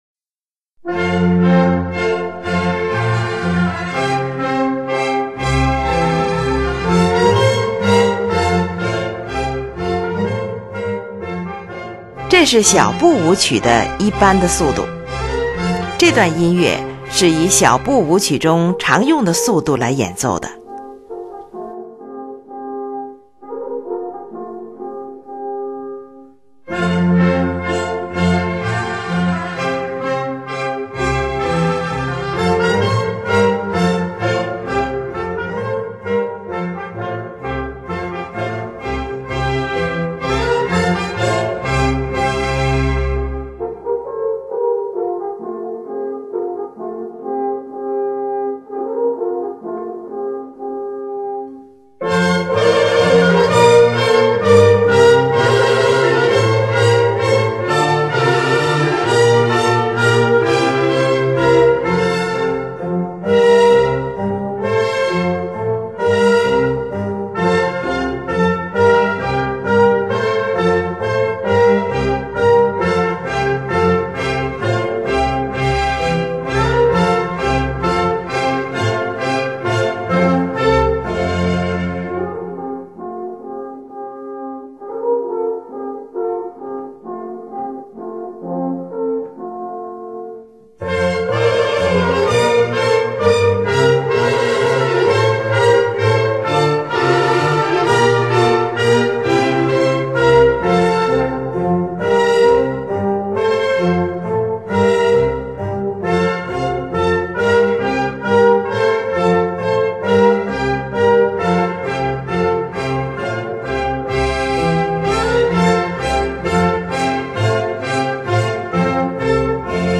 in F Major
4）这是小步舞曲的一般的速度。这里指音乐是以小步舞曲中常选用的速度来进行的。
乐器使用了小提琴、低音提琴、日耳曼横笛、法兰西横笛、双簧管、圆号、小号等